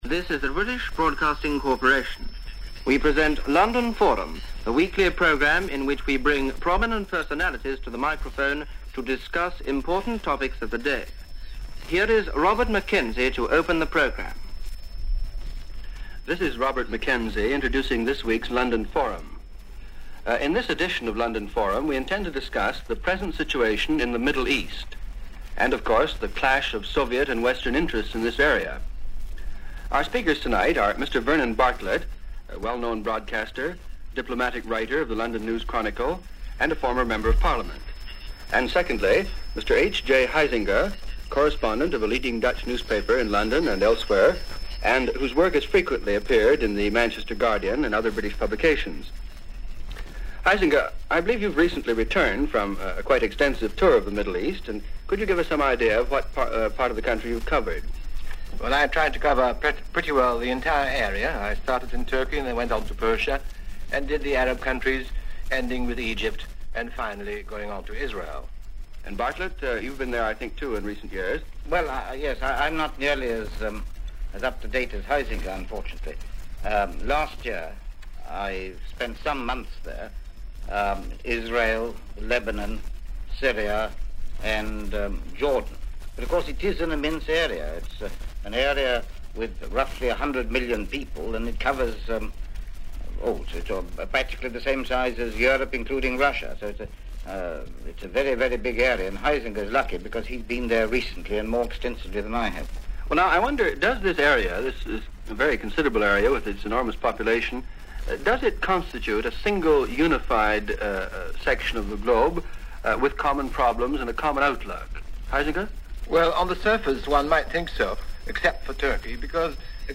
Middle East Changes - Nationalism And Colonialism - Twists, Turns And Overthrows - April 16, 1951 - Discussion from The BBC World Service.